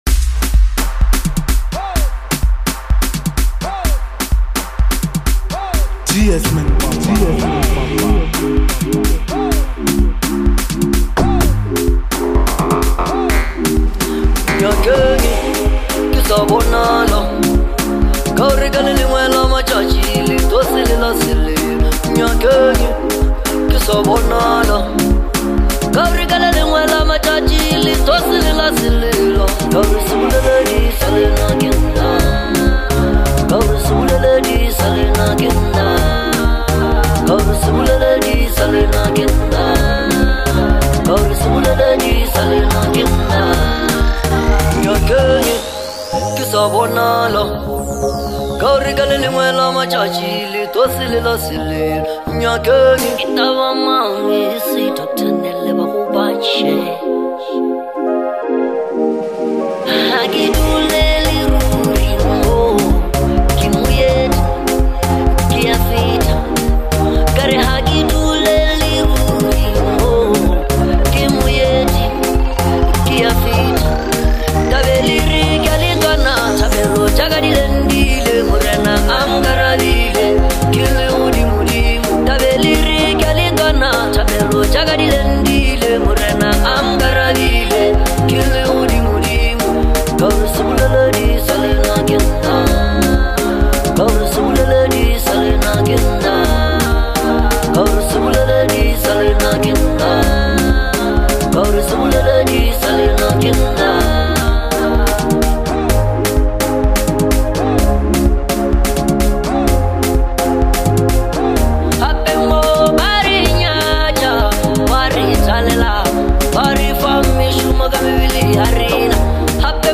Lekompo
bolo house